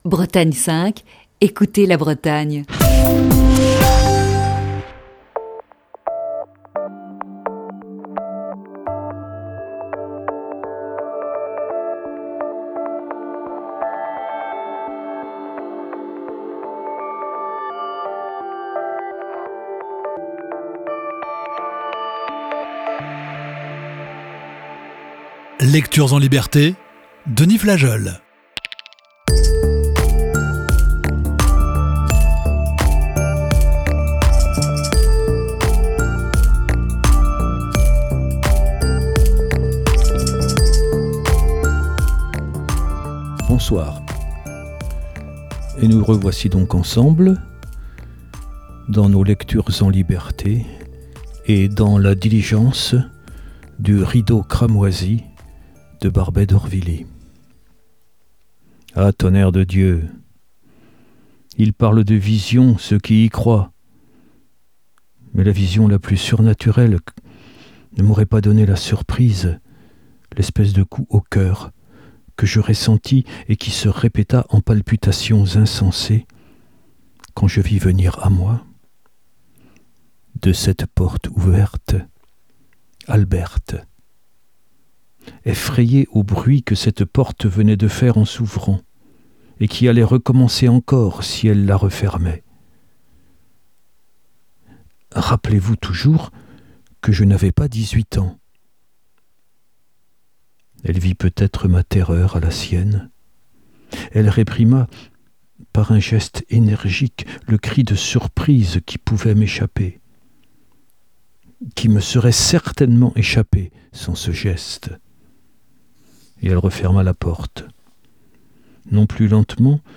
Émission du 24 septembre 2020.